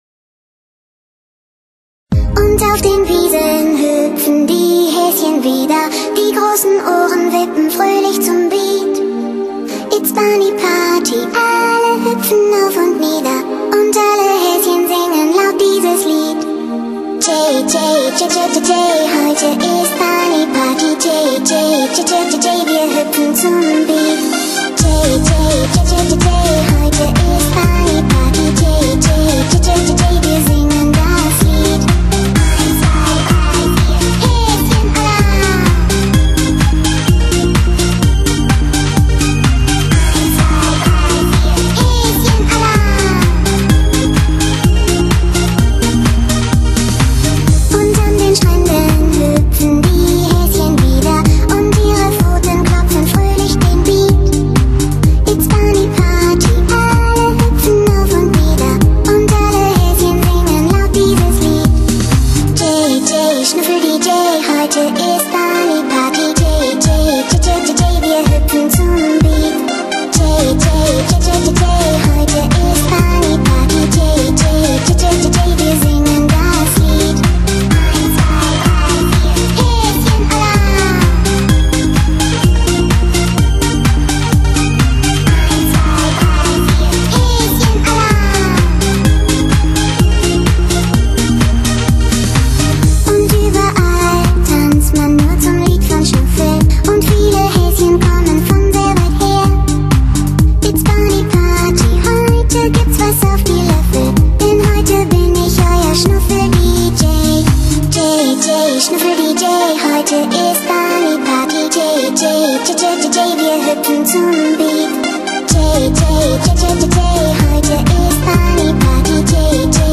一种全新理念的酒吧音乐，时尚、刺激、有情调、氛围好，音乐风格是多样化、风格化，
随意性比较强，在曲调空隙间留给他人有想象的空间，注重现场气氛的释放中低频段层次
清晰分明，扩声均匀，中高不刺，温暖柔和。